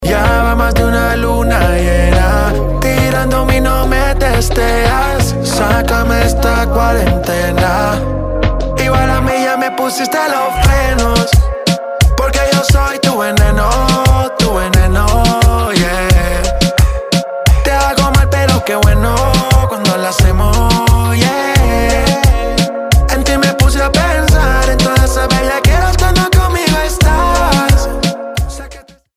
Latino